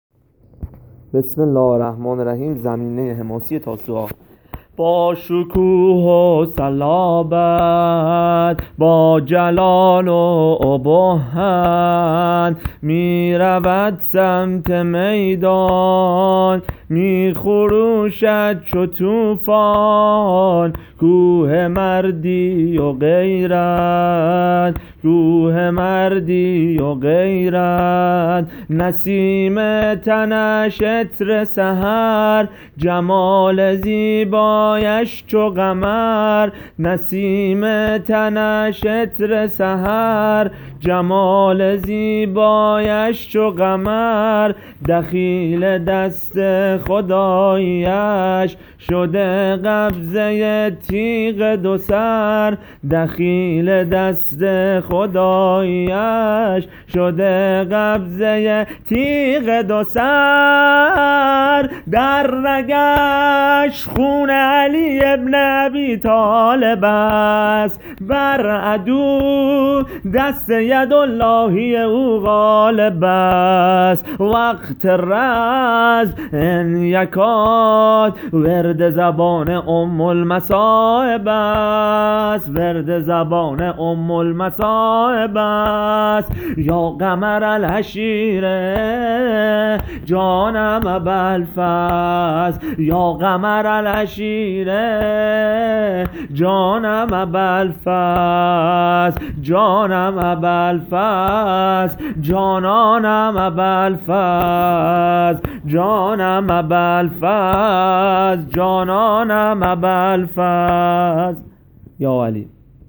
زمینه حماسی تاسوعا قمر منیر بنی هاشم حضرت اباالفضل العباس(ع) -(با شکوه و صلابت،با جلال و ابهت)